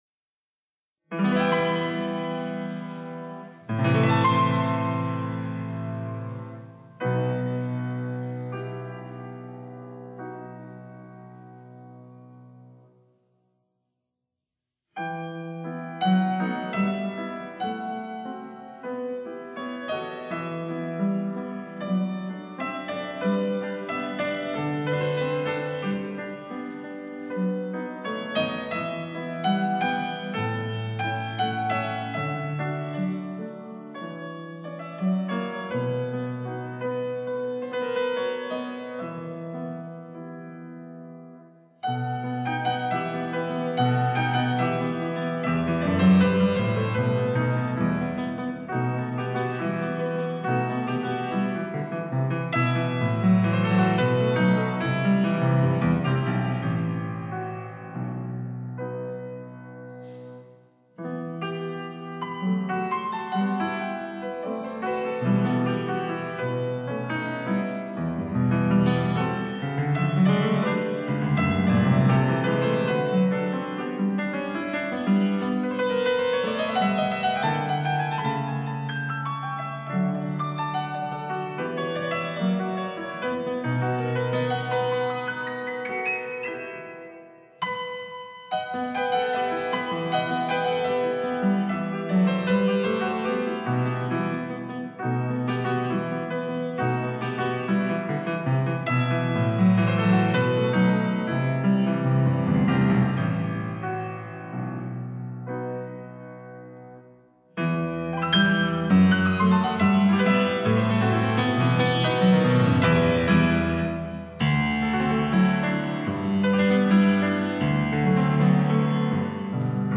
پیانو
یک پارچه  پیانو